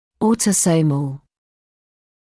[ˌɔːtə’səuməl][ˌоːтэ’соумэл]аутосомный